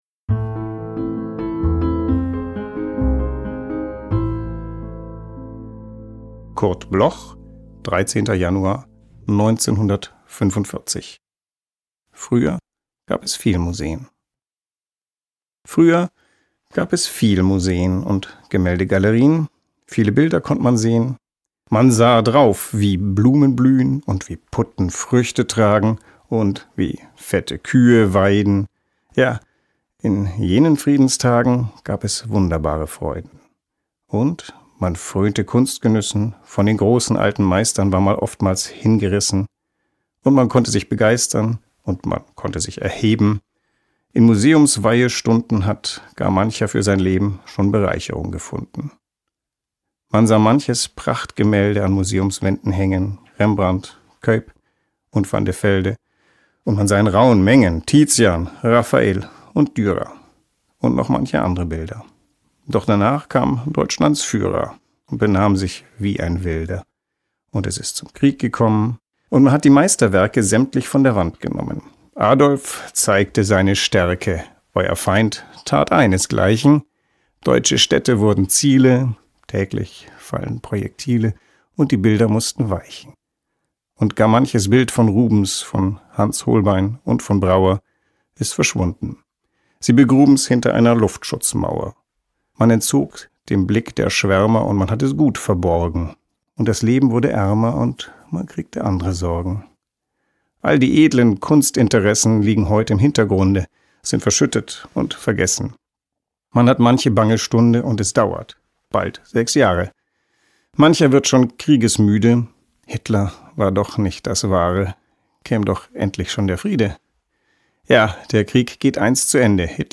voorgedragen